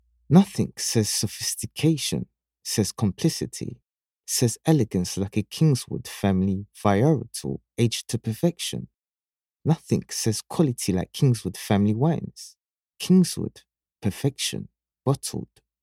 English (Caribbean)
Adult (30-50) | Yng Adult (18-29)